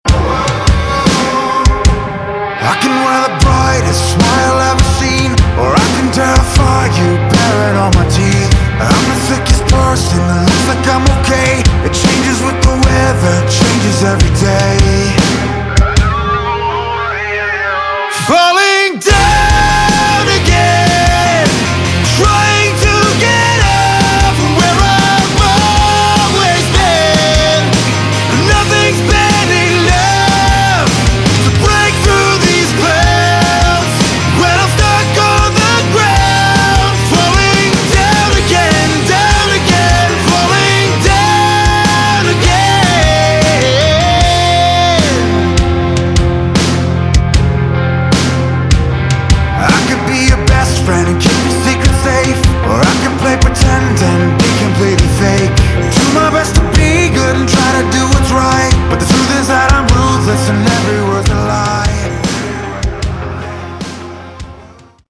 Category: Modern Hard Rock
lead guitar, vocals
drums
bass